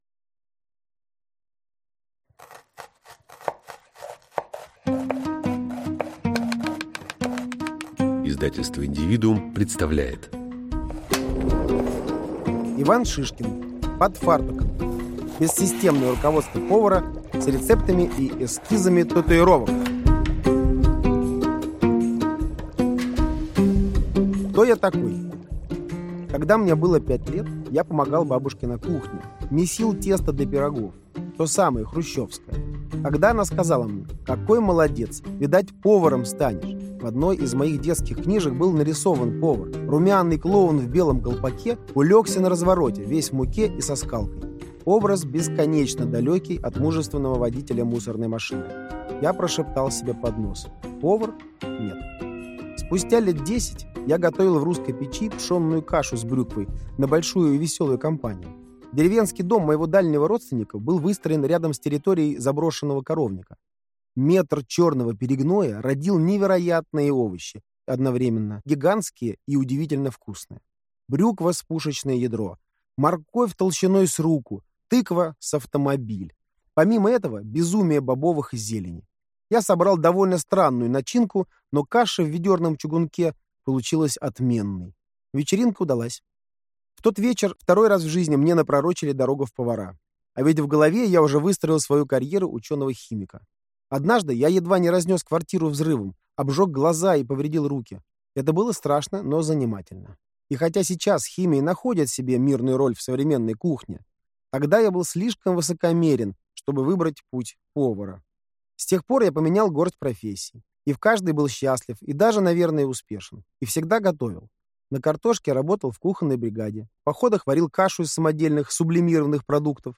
Aудиокнига Под фартуком.